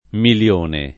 milL1ne] s. m. — non millione; così pure, err. le varianti con -ll- dei der. milioncino, milionesimo, milionario (con multimilionario) e delle voci bilione, trilione, quadrilione, quintilione e miliardo (con miliardesimo, miliardario e multimiliardario), indicanti multipli del milione e ricavate da milione con la sovrapposiz. d’un prefisso (bi-, tri-, ecc.) o, risp., con la sostituz. del suffisso -ardo a -one — gf. incerta per secoli, presso chi scriveva in lat., per milia «migliaia»: -l- doppia (millia) come in mille, oppure scempia (milia, forma oggi riconosciuta più corretta)?; e sim. per quei der. lat. di mille in cui l’-l- è seguìta da un -i-, con qualche riflesso in it.